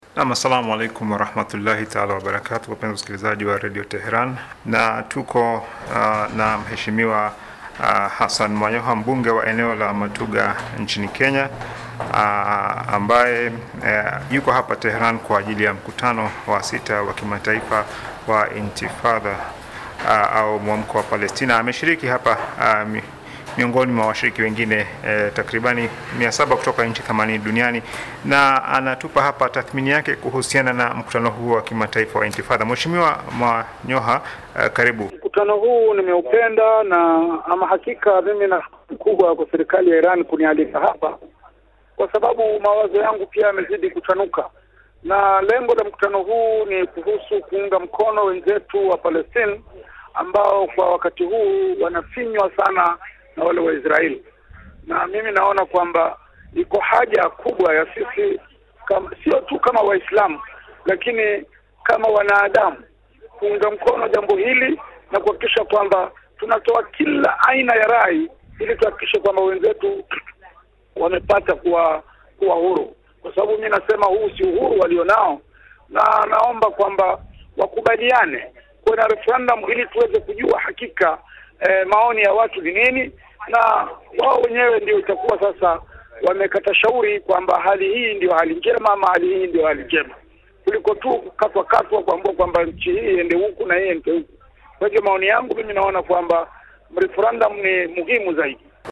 Sikiliza mahojiano kupata tathmini yake kamili ya mkutano huo